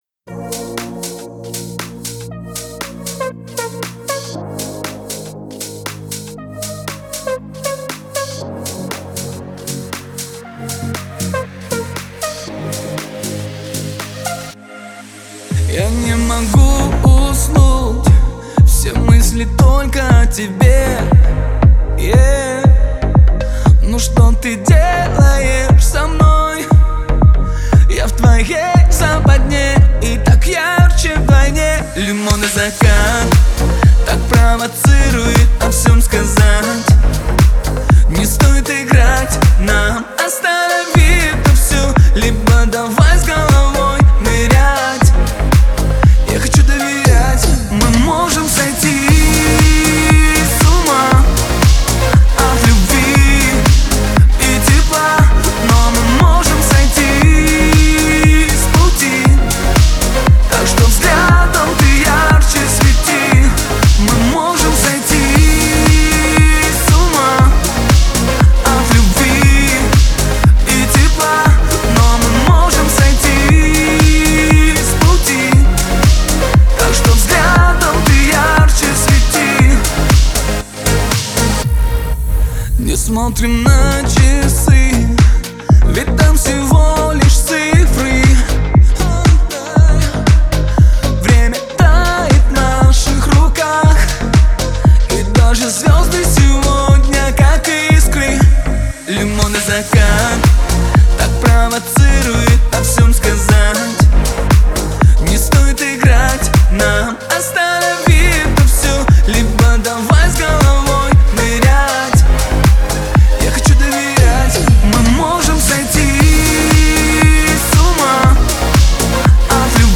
Жанр: Русские